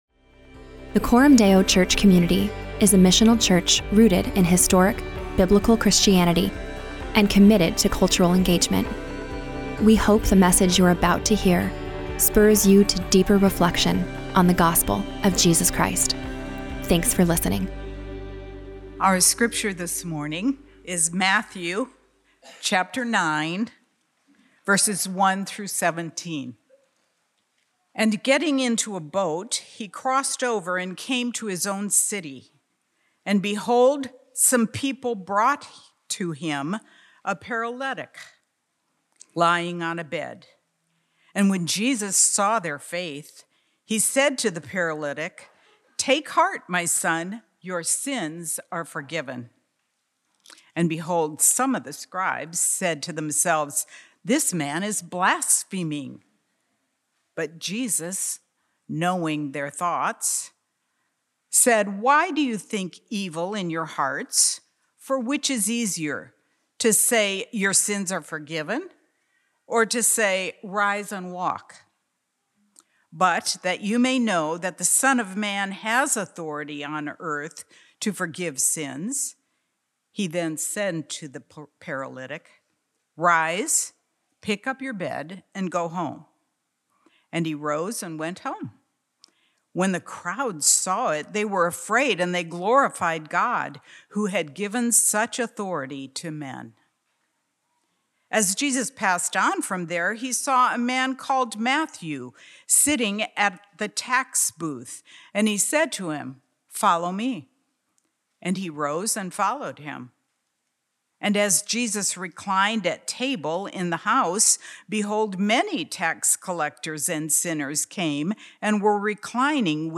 Weekly sermons from Coram Deo Church in Omaha, NE.